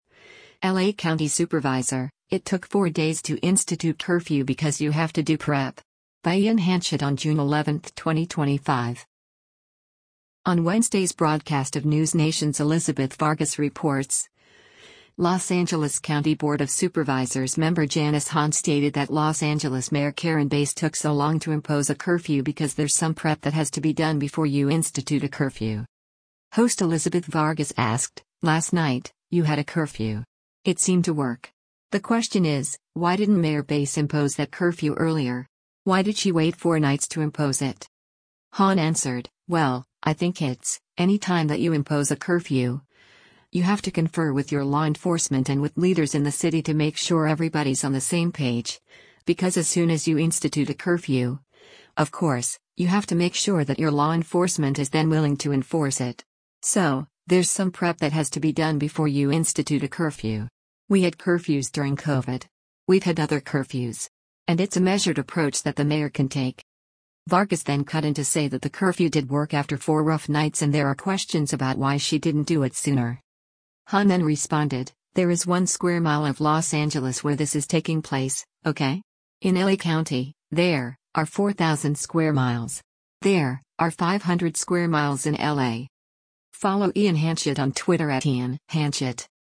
On Wednesday’s broadcast of NewsNation’s “Elizabeth Vargas Reports,” Los Angeles County Board of Supervisors member Janice Hahn stated that Los Angeles Mayor Karen Bass took so long to impose a curfew because “there’s some prep that has to be done before you institute a curfew.”
Vargas then cut in to say that the curfew did work after four rough nights and there are questions about why she didn’t do it sooner.